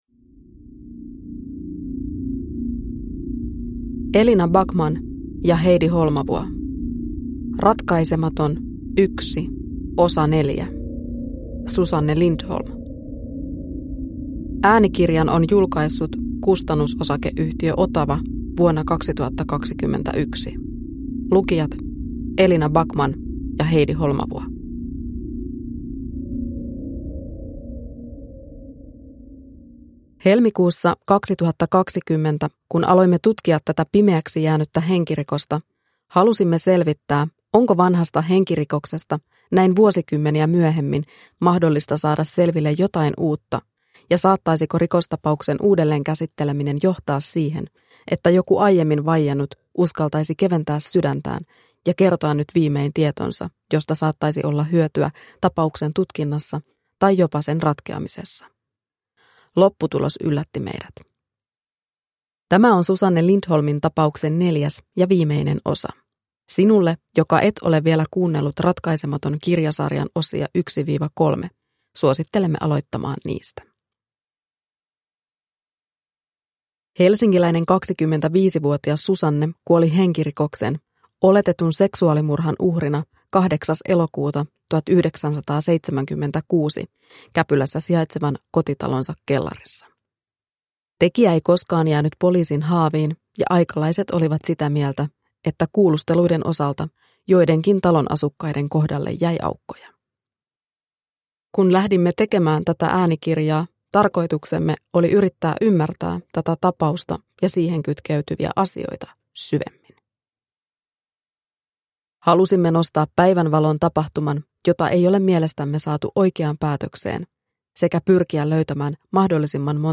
Ratkaisematon 4 (ljudbok